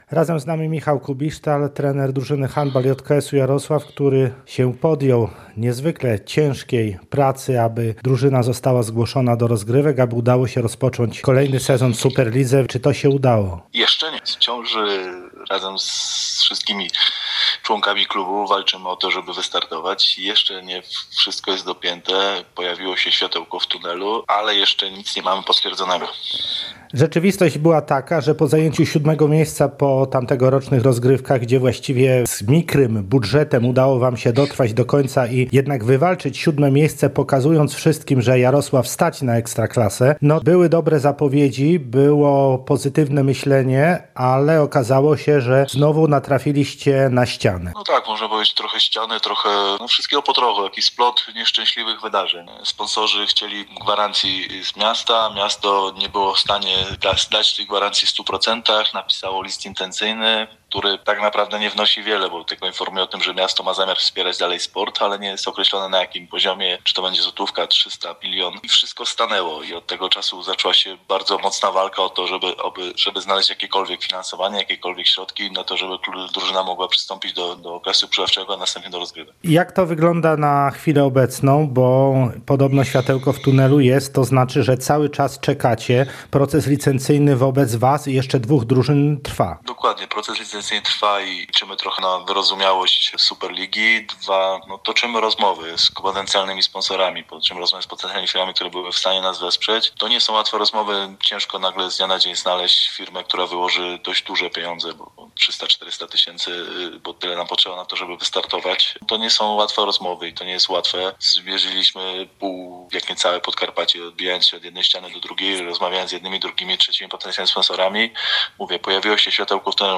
Czy uda się przystąpić do rozgrywek – o tym w rozmowie